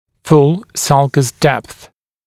[ful ˈsʌlkəs depθ][фул ˈсалкэс дэпс]полная глубина борозды